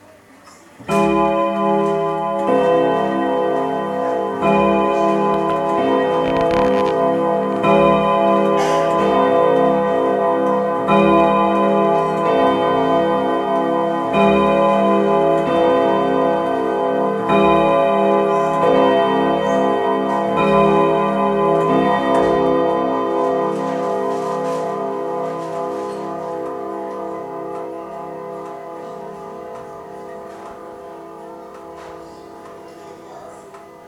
old_clock_beating_seven
beating clock cymbal deep ding dong gong hour sound effect free sound royalty free Sound Effects